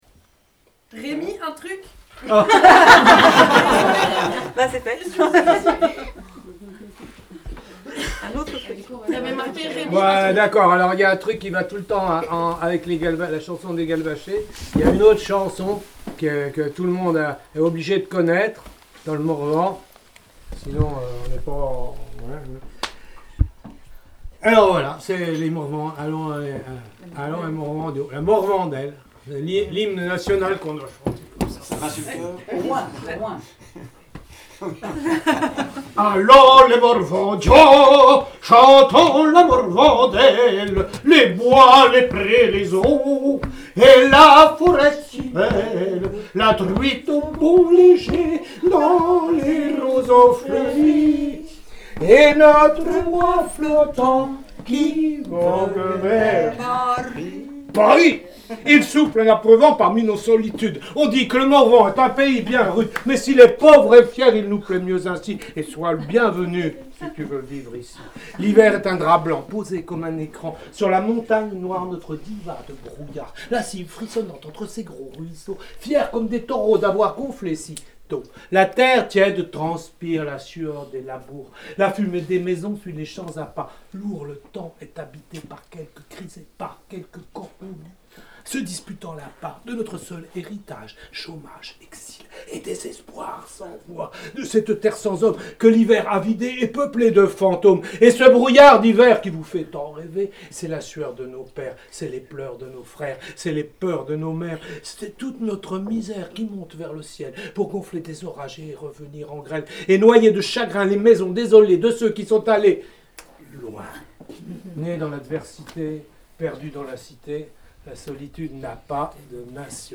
RAP morvandiau